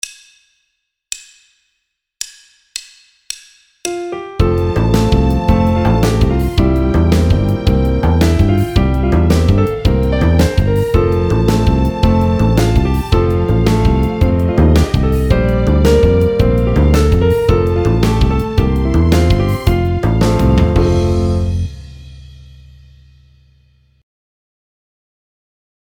Piano & Accompaniment